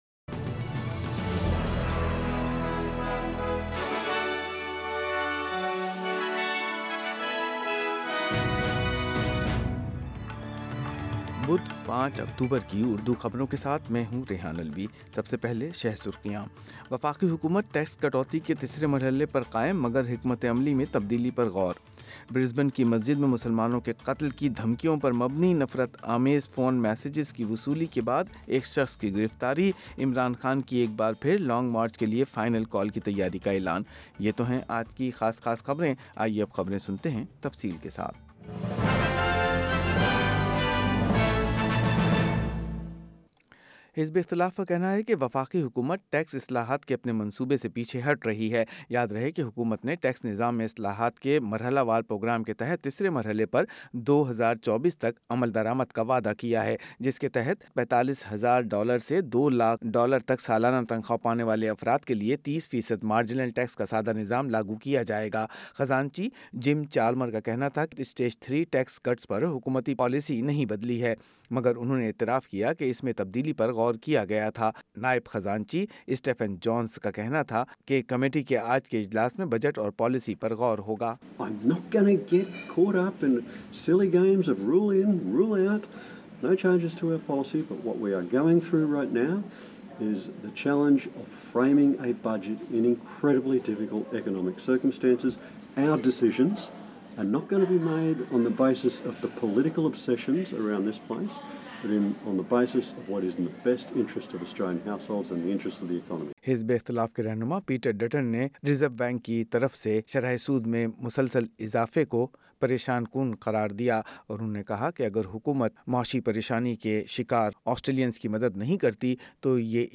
Full news bulletin in Urdu - Wednesday 5 October 2022